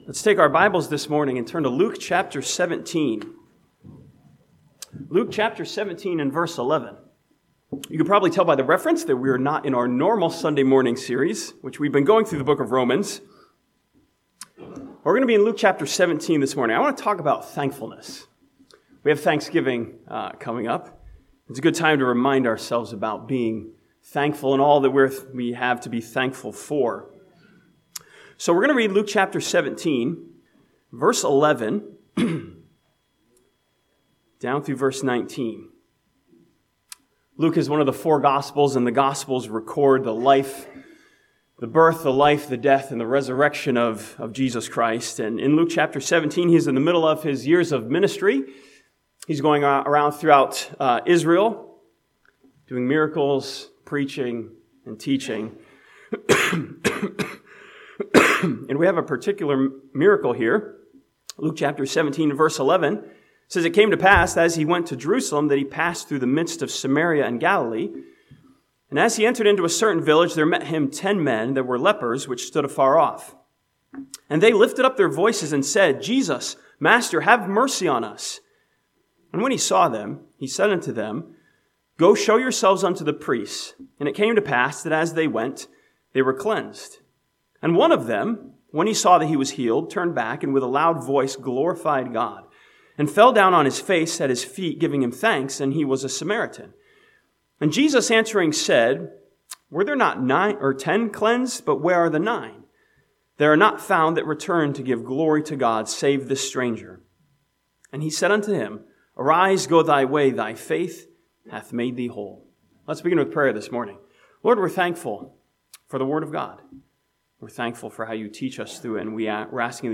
This sermon from Luke chapter 17 takes a look at the nine men who were healed by Jesus but did not return to say 'thank you'.